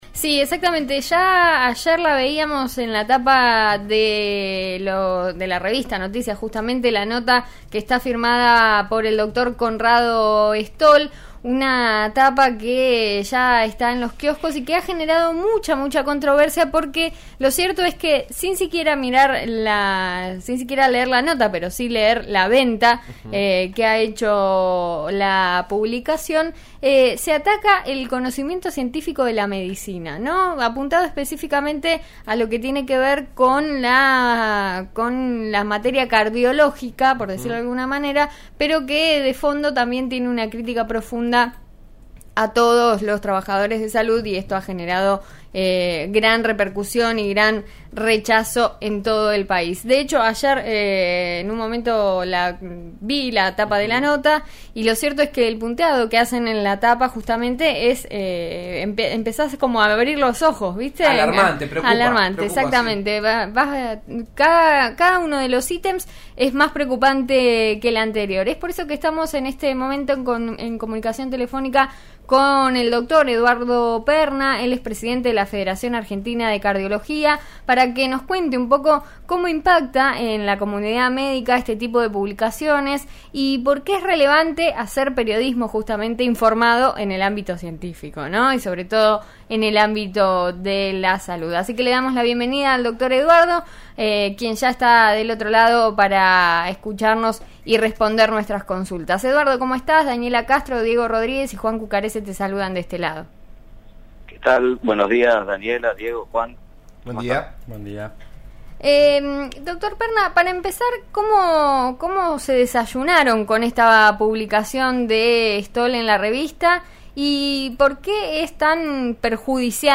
dialogó a En eso estamos de RN Radio 89.3 sobre el repudio a una nota publicada por una reconocida revista.